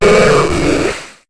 Cri de Magicarpe dans Pokémon HOME.